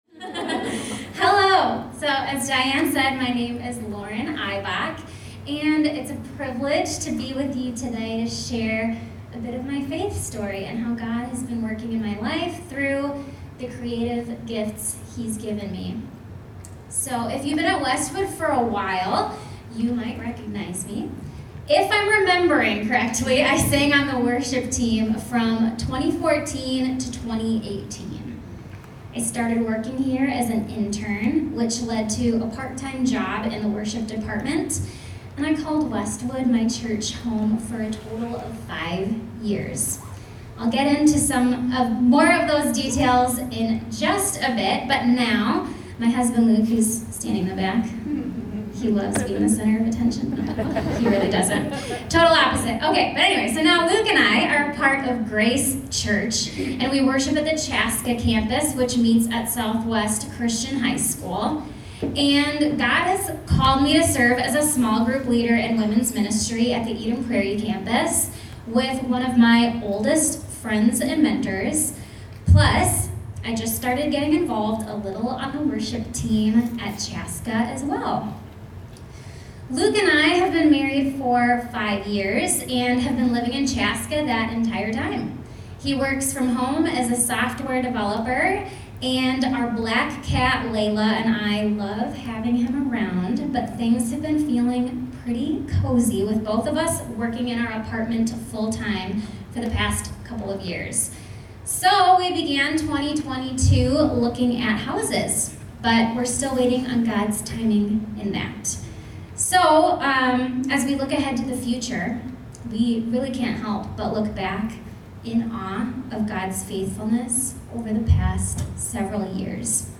Recently, I was invited by a group of young moms at my former church to share my faith story and creative journey.